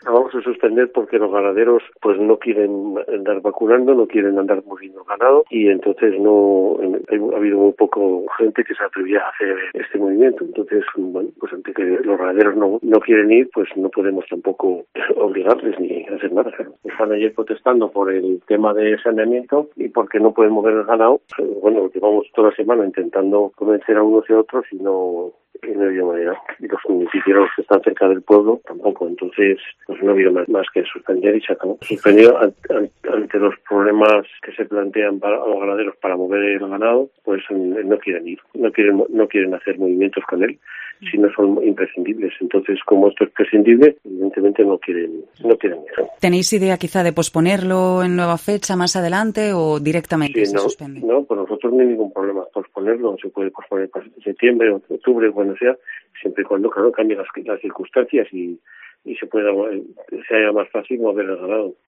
AUDIO: El alcalde de La Fuente de San Esteban, Manuel Rufino García confirma en declaraciones a Cope que la feria se